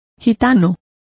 Complete with pronunciation of the translation of gypsies.